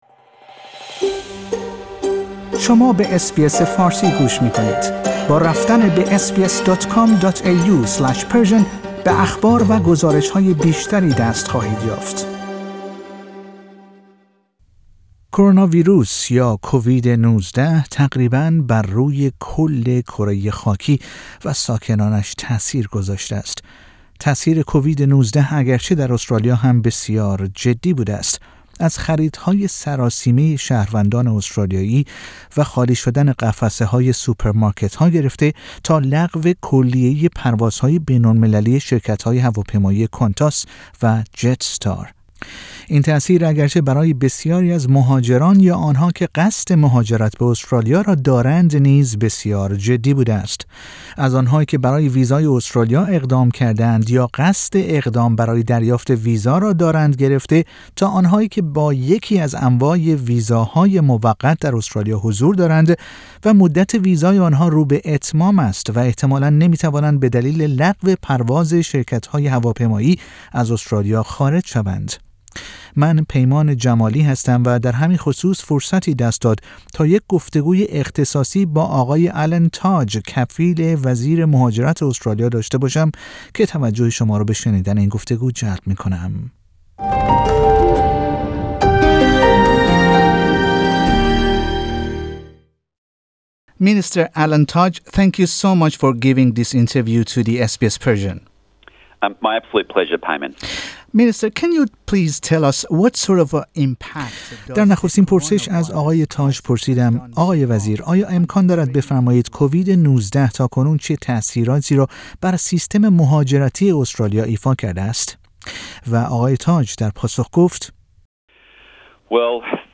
همزمان با گسترش کروناویروس (کووید-۱۹) در بسیاری از کشورهای جهان، برنامه فارسی رادیو اس بی اس ساعاتی پیش در یک گفتگوی اختصاصی با آقای آلن تاج کفیل وزیر مهاجرت استرالیا تعدادی از پرسش هایی را که پاسخ آن برای جامعه مهاجر ایرانی ساکن استرالیا مهم است، مطرح کرد.